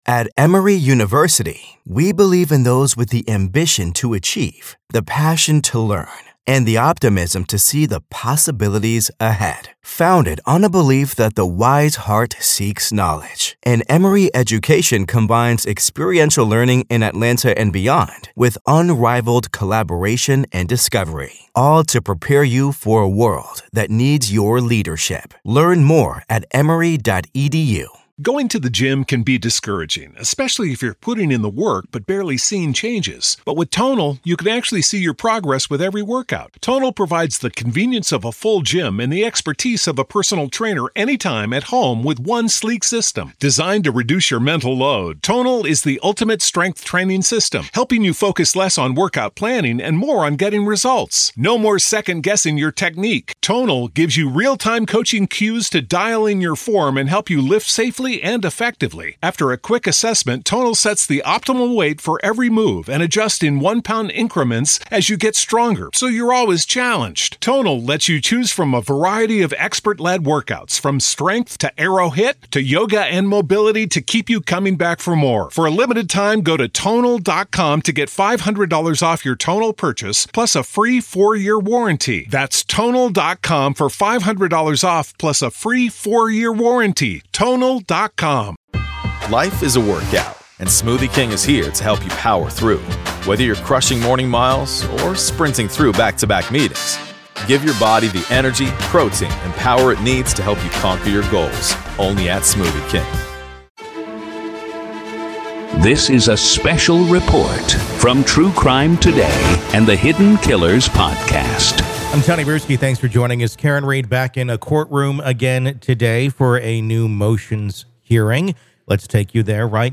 RAW COURT AUDIO: Judge Halts Karen Read Pretrial Hearing After ‘Grave Concern’ Over New Information PART 2